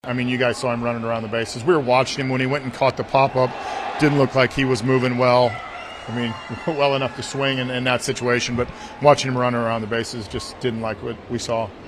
Nick Gonzalez came up limping on his home run trot.  Shelton says he took two batted balls off the ankle in an exhibition game against Tampa Bay eight days earlier.